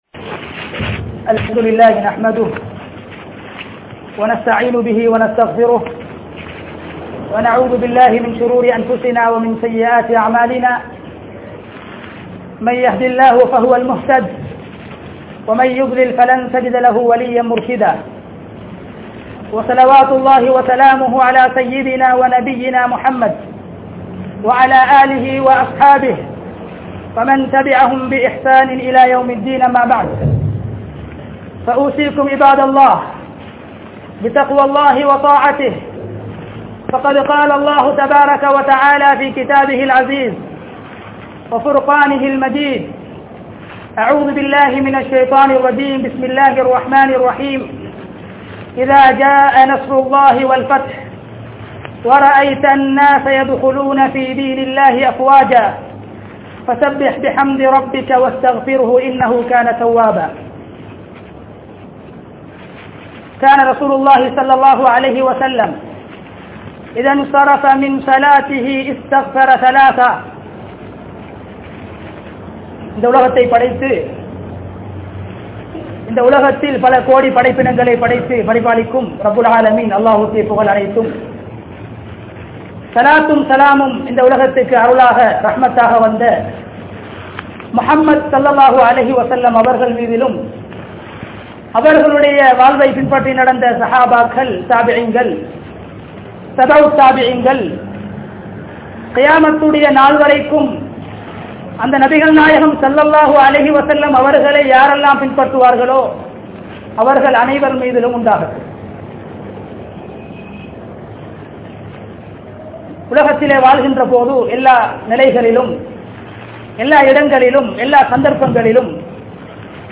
Ramalan Maathathin Iruthi Amalhal (ரமழான் மாதத்தின் இறுதி அமல்கள்) | Audio Bayans | All Ceylon Muslim Youth Community | Addalaichenai
Jamiul Falah Jumua Masjidh